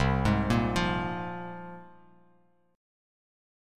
C7sus4#5 chord